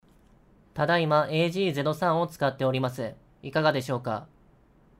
※使用したマイクは「オーディオテクニカ AT2035」です。
若干AG03の方がクリアな感じですが、しっかり聴かないとわからないレベルなので、普通に価格以上の性能があると思います。